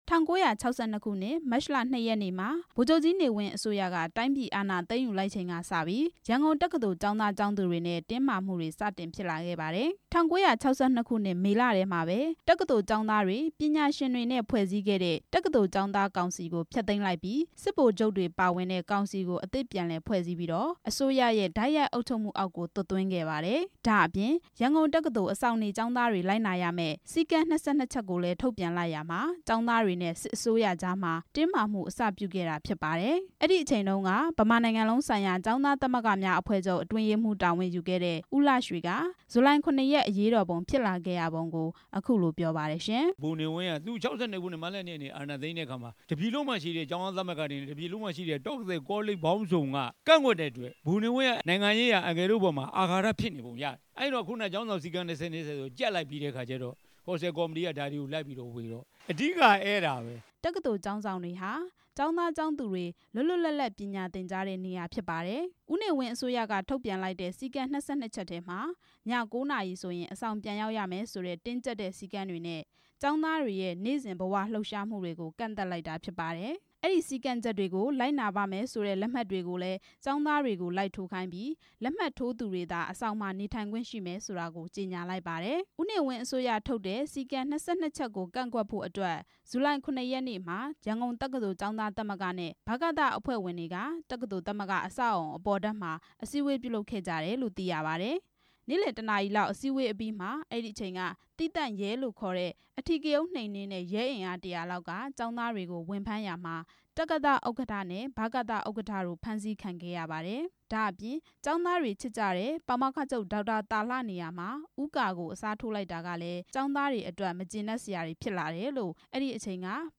ဆဲဗင်းဂျူလိုင်ကို ဖြတ်သန်းခဲ့သူတချို့ရဲ့ ပြောကြားချက်